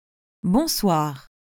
🎧 Bonsoir pronunciation in French
bɔ̃.swaʁ/ (IPA), which sounds roughly like “bon SWAHR.”
1-bonsoir.mp3